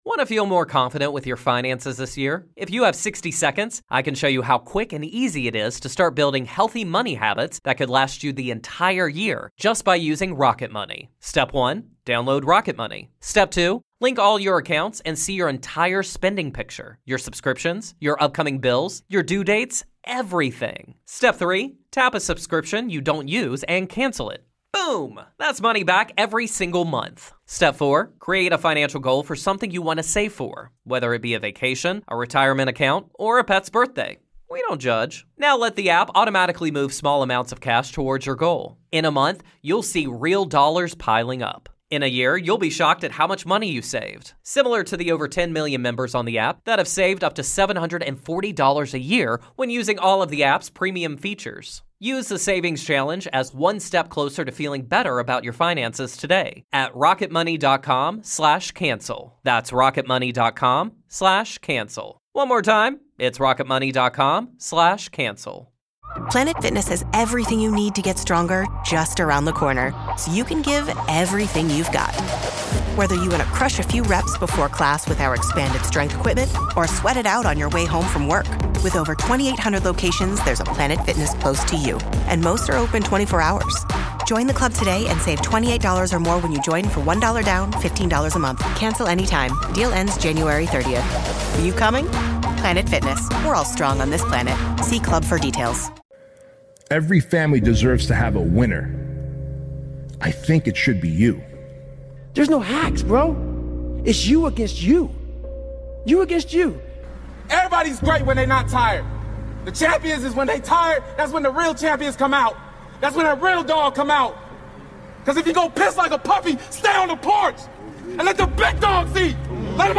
Featuring voices like Goggins, Hormozi, Jocko, Rogan, and more — this is for the ones who live in the shadows and earn in silence.